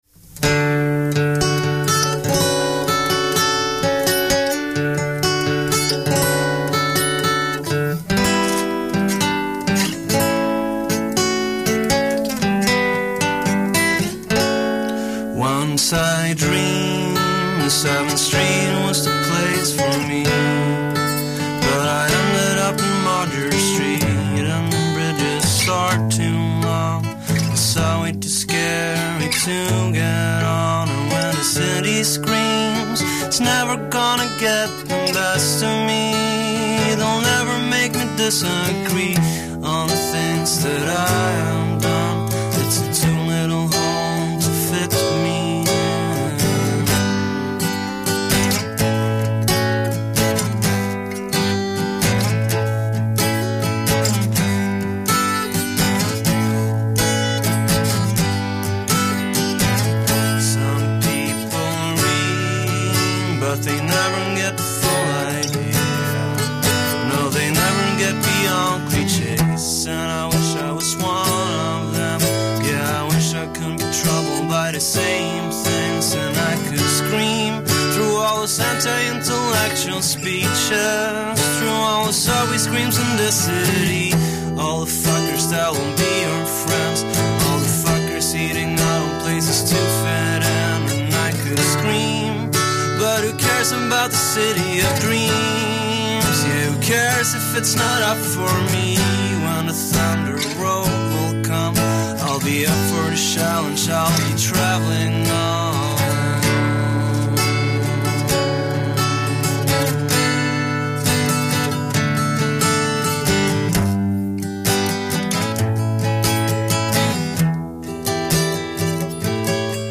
live unplugged